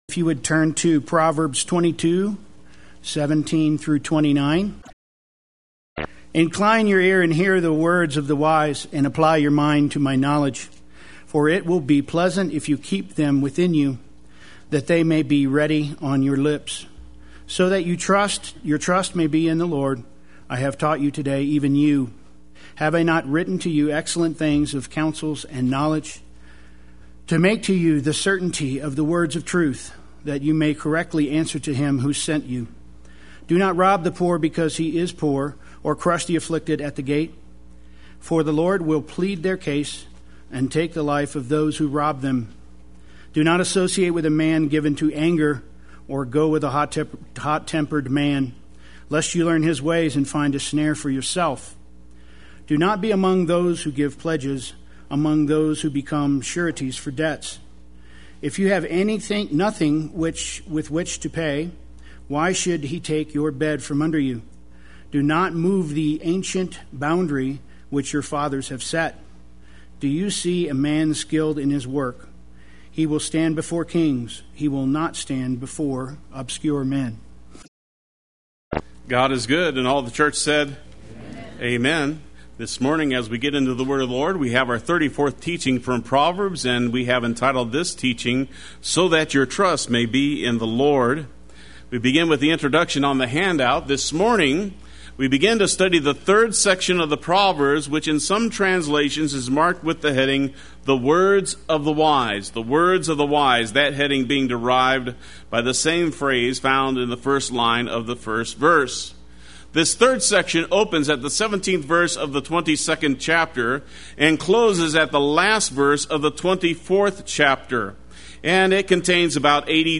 Play Sermon Get HCF Teaching Automatically.
So That Your Trust May Be in the Lord Sunday Worship